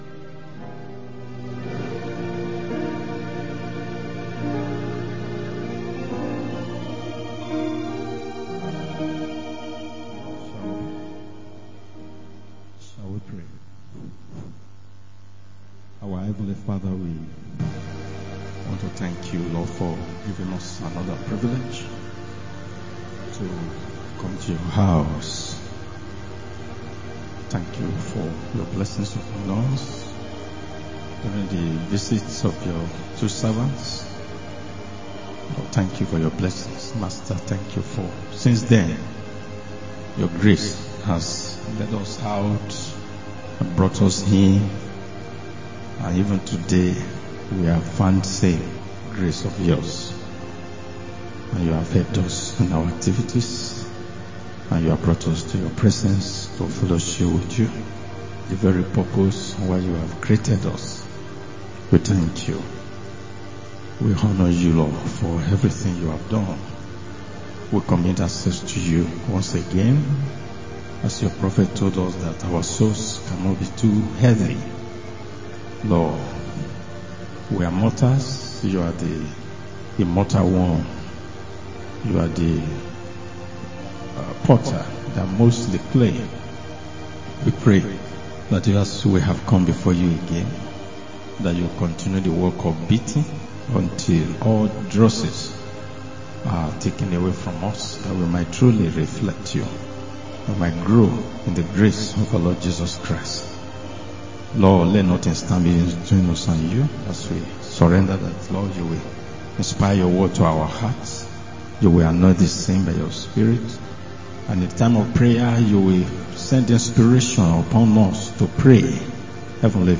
Wed. Prayer Meeting 24-09-25